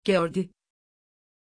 Pronunciación de Geordie
pronunciation-geordie-tr.mp3